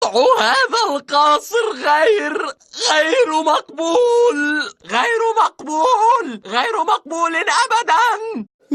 Play, download and share unaccptlb original sound button!!!!
lemongrab-unacceptable-in-13-language-mp3cut.mp3